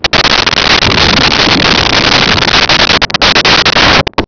Thunder
Thunder.wav